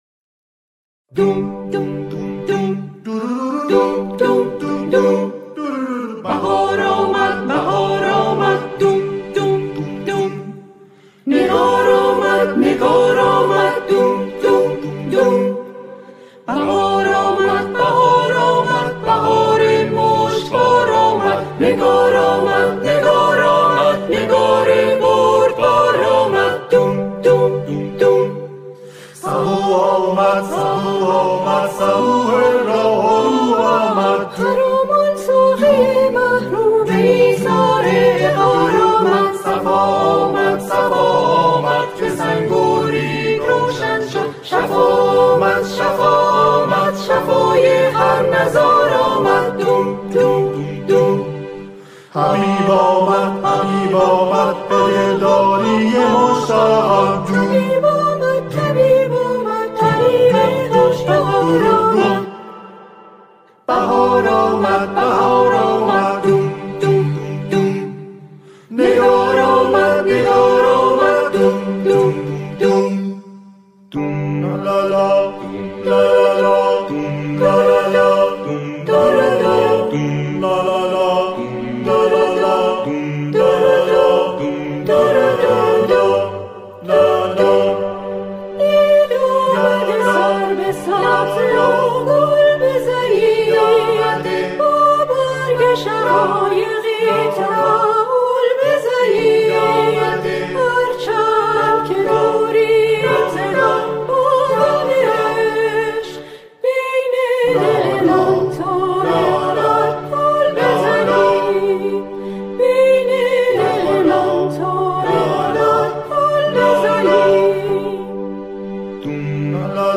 سرودهای دهه فجر
آنها در این قطعه، شعری را درباره دهه فجر همخوانی می‌کنند.